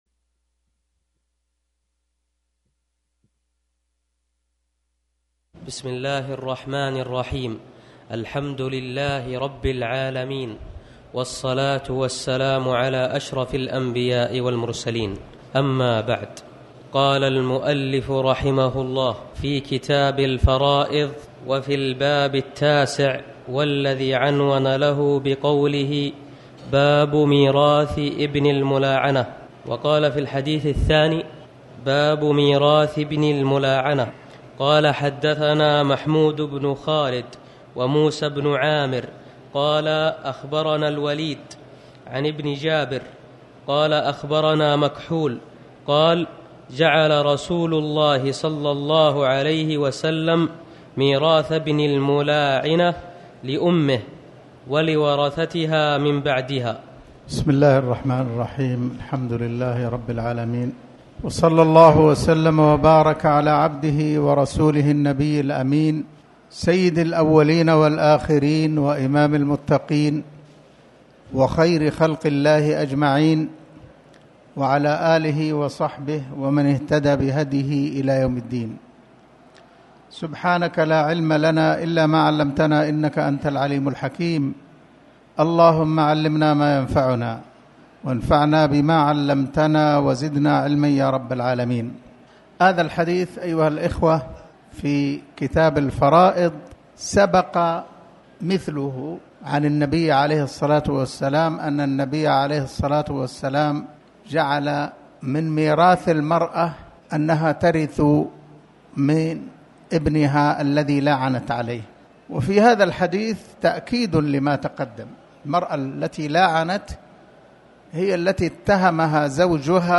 تاريخ النشر ٤ رجب ١٤٣٩ هـ المكان: المسجد الحرام الشيخ